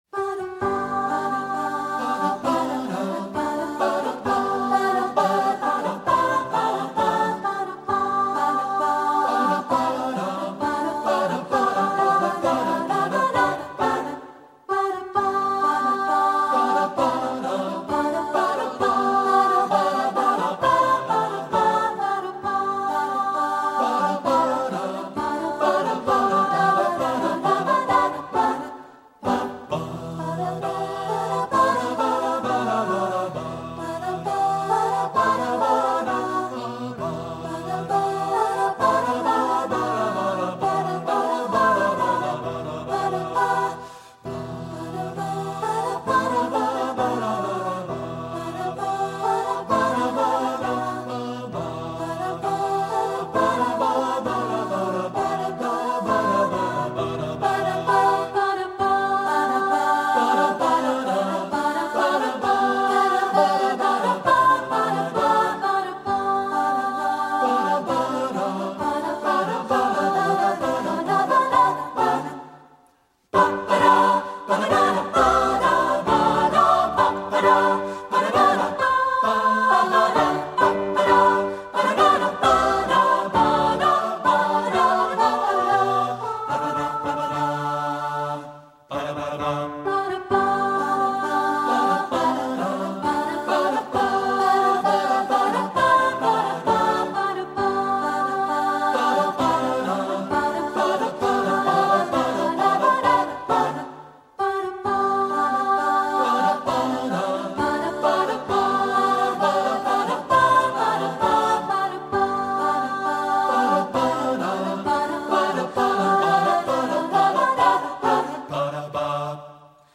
Voicing: SAB a cappella